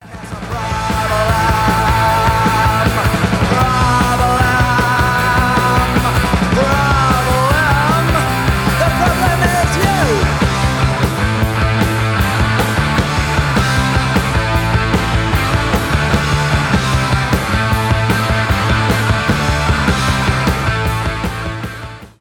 панк-рок